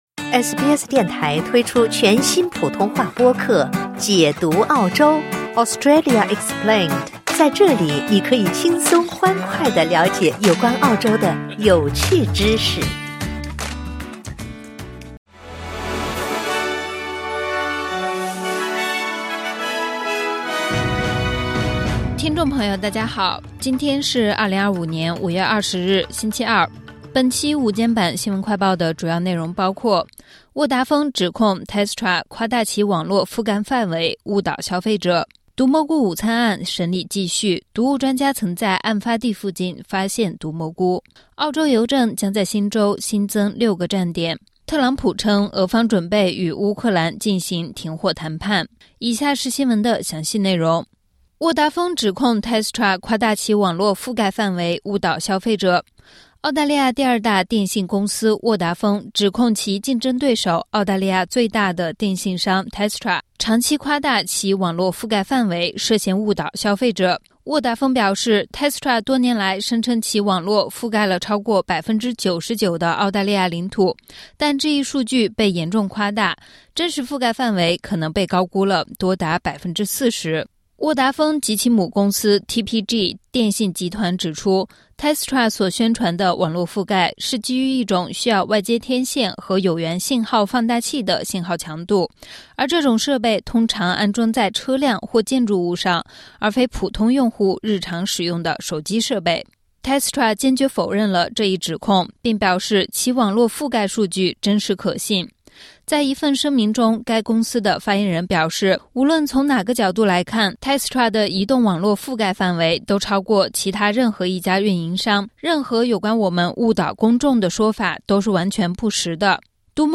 【SBS新闻快报】Telstra被指夸大其网络覆盖范围 误导消费者